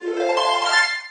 chime_4.ogg